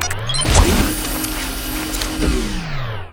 battlesuit_remove.wav